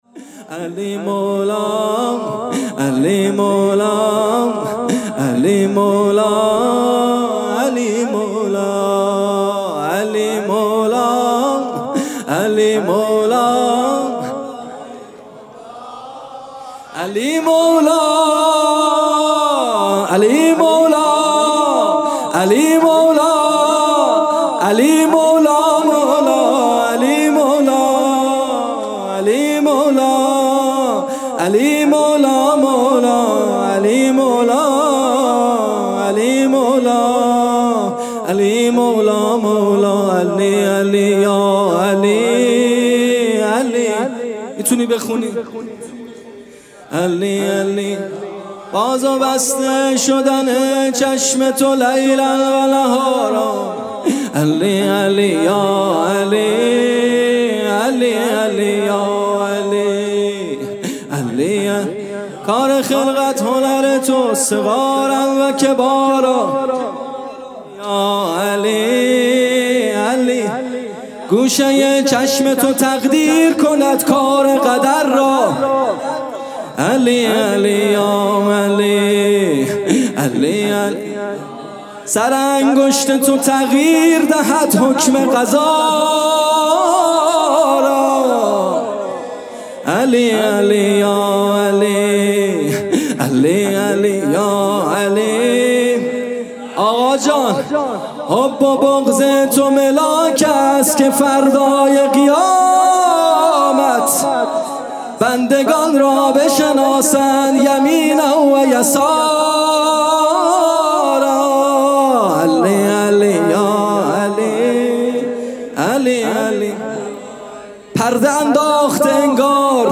جلسه هفتگی
music-icon سرود